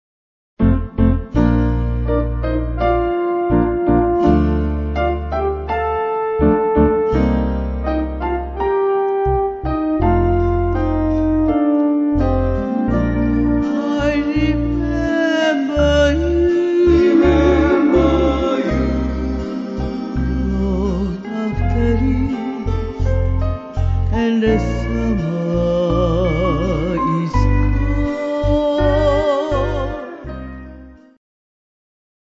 Steel Guitar & Chorus
Piano, Keyboards,
Acoustic Guitar & Bass
Ukulele